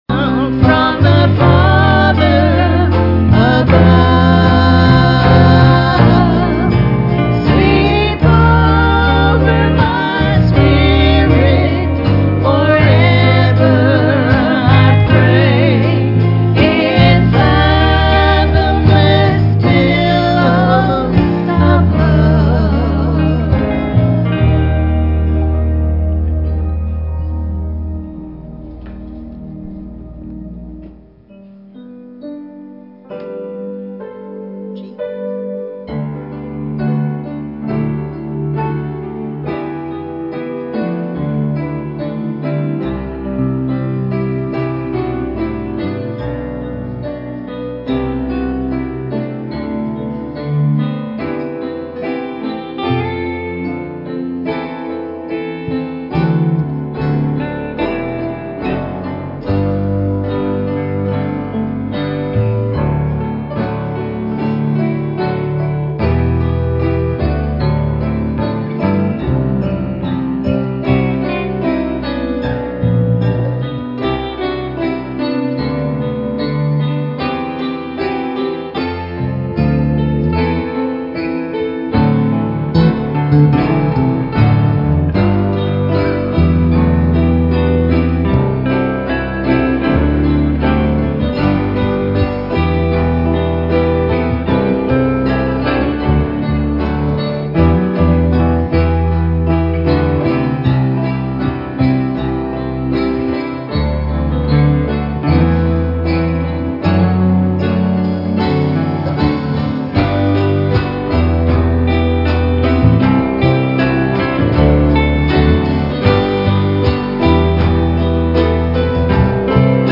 A Night of Singing